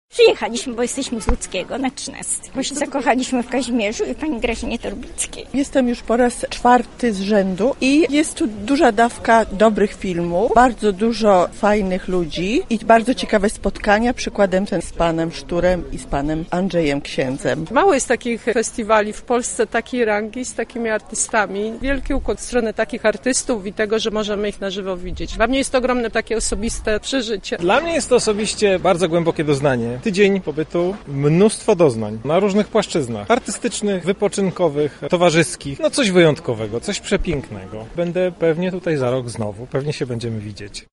O wrażenia z festiwalu zapytaliśmy publiczność.